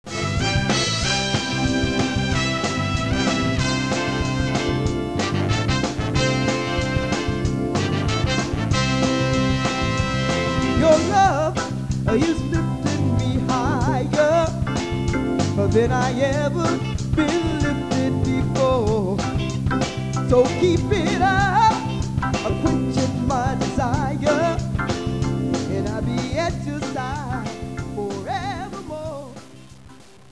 Lead Vocal
Trombone Solo